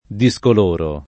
discoloro [ di S kol 1 ro ]